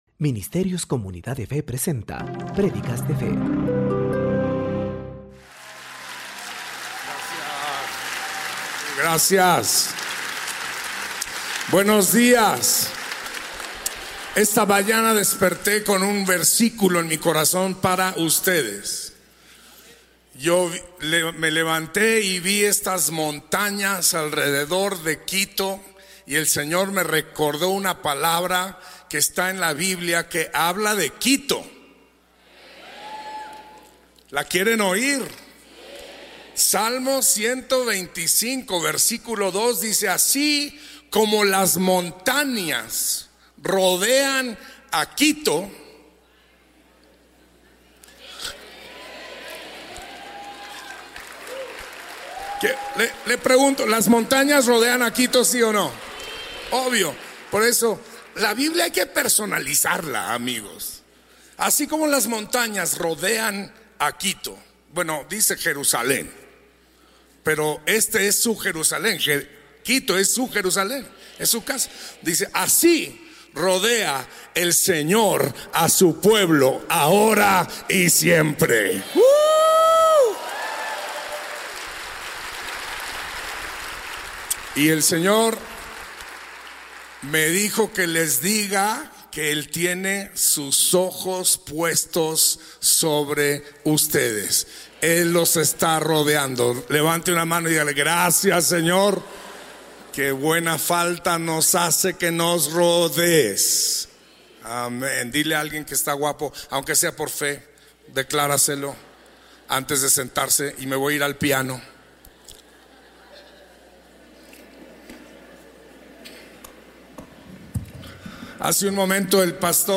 Cómo ser luz en este mundo Por: Pastor Marcos Witt